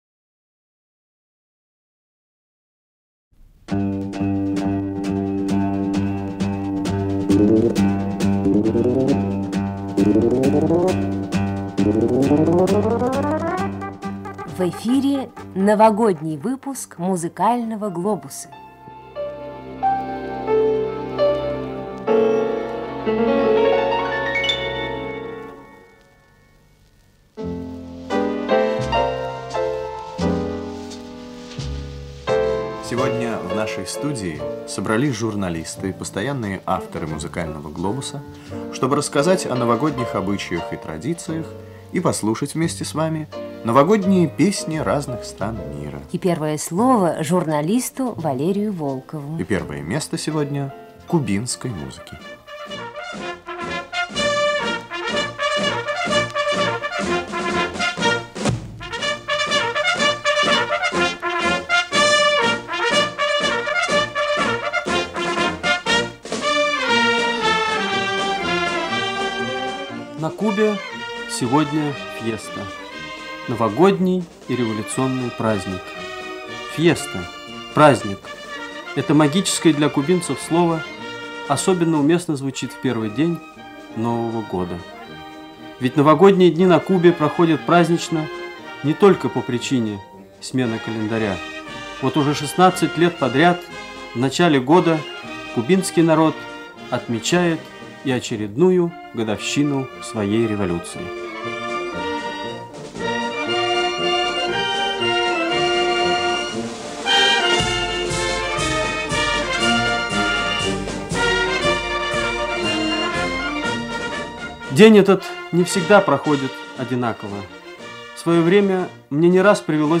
"Музыкальный глобус" № 276. Новогодняя радиопередача (1974)
Новогодняя радиопередача (1974) Новогодние песни разных стран.